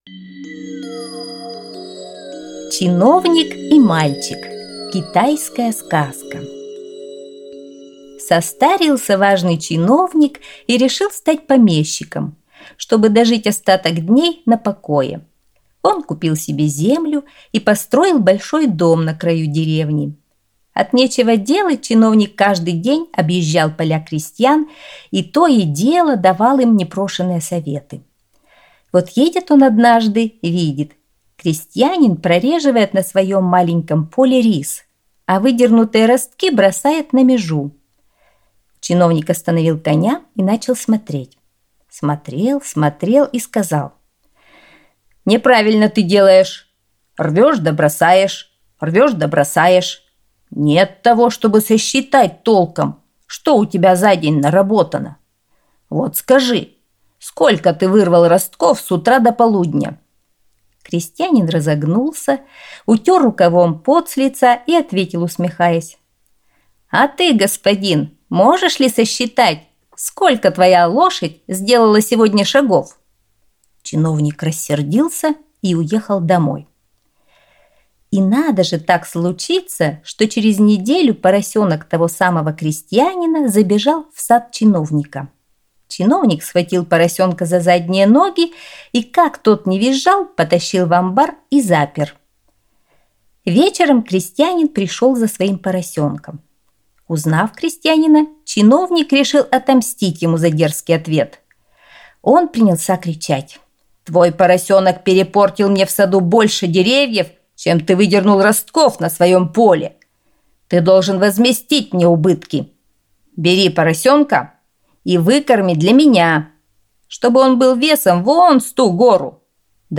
Чиновник и мальчик – китайская аудиосказка